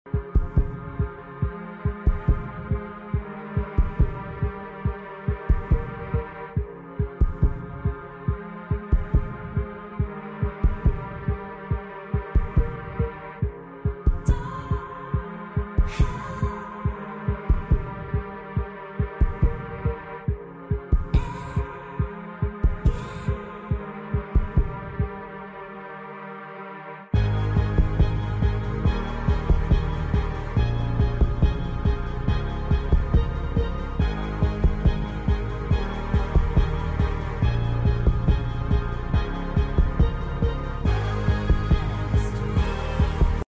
Long intro edit audio
Pop Music
Instrumentals